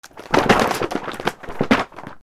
雑誌が落ちる音 06 Powerful
/ J｜フォーリー(布ずれ・動作) / J-10 ｜転ぶ　落ちる